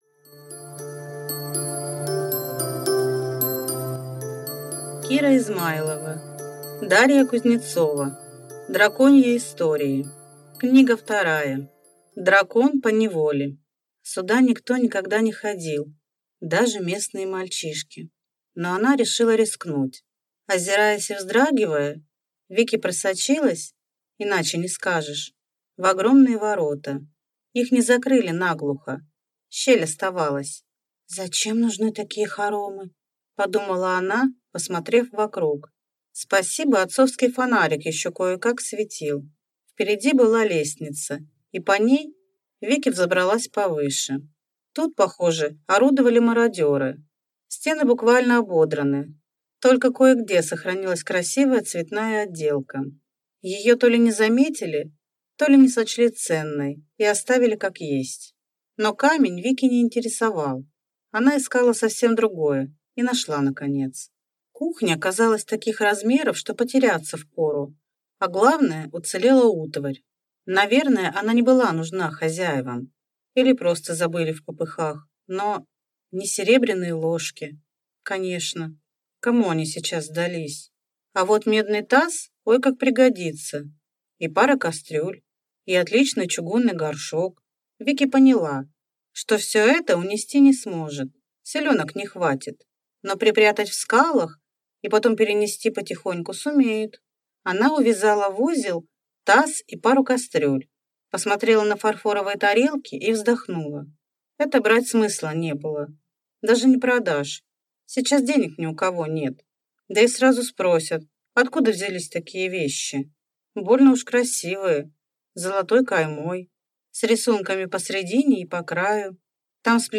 Аудиокнига Драконьи истории. Книга вторая | Библиотека аудиокниг